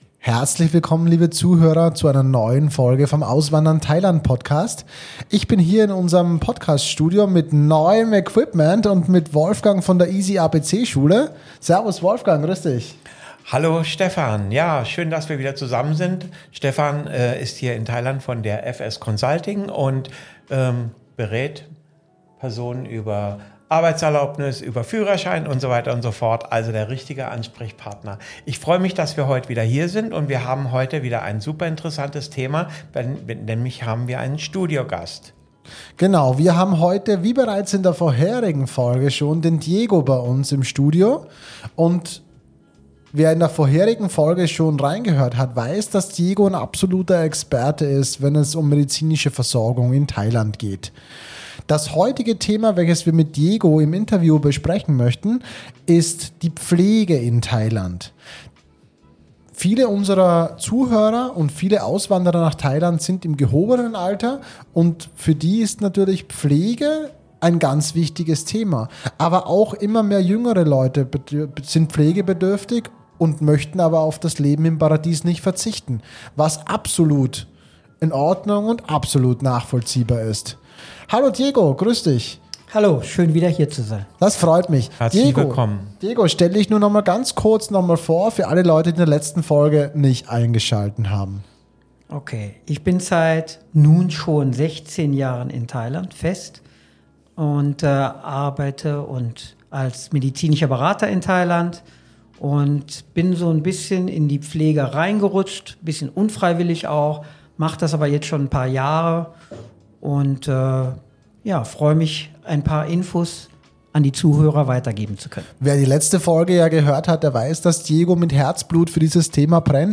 Pflege in Thailand: Kosten, Betreuung und Möglichkeiten für Auswanderer. Experteninterview mit medizinischem Berater zu Vor- und Nachteilen gegenüber Europa.